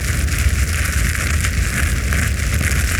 fireloop.wav